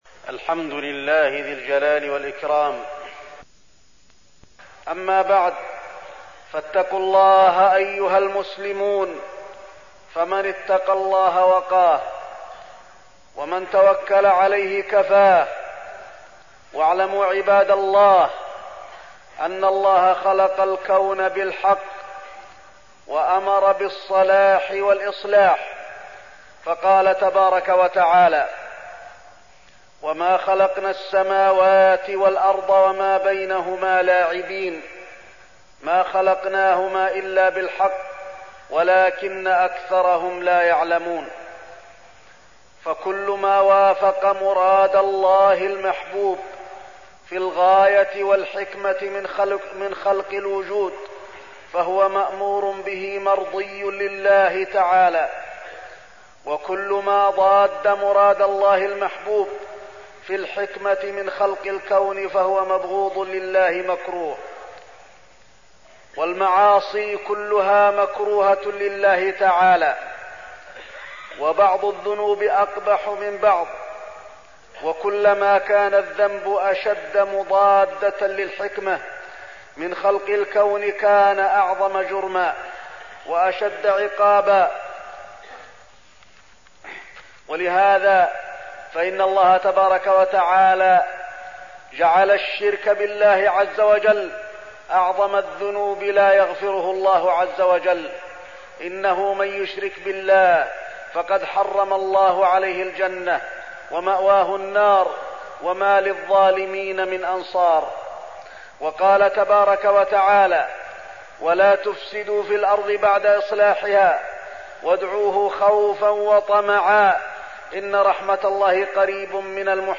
تاريخ النشر ٢٨ شوال ١٤١٧ هـ المكان: المسجد النبوي الشيخ: فضيلة الشيخ د. علي بن عبدالرحمن الحذيفي فضيلة الشيخ د. علي بن عبدالرحمن الحذيفي الكبائر والزنا